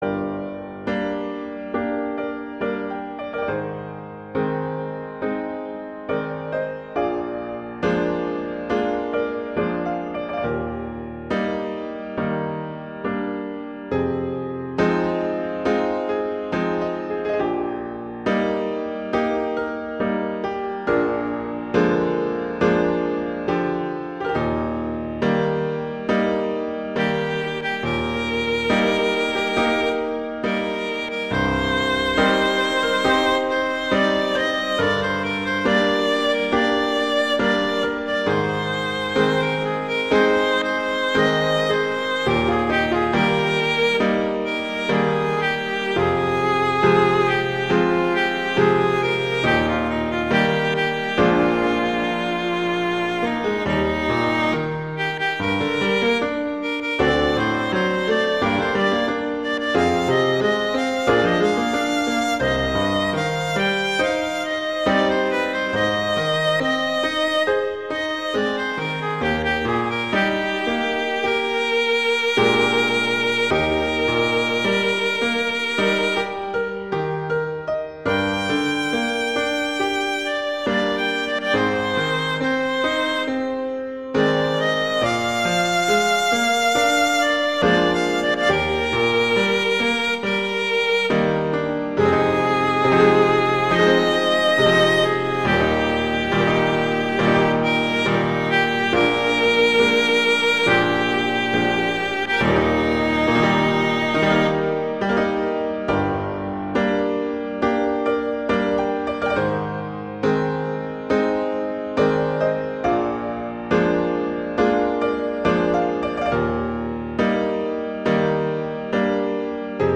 Instrumentation: violin & piano
classical, world, children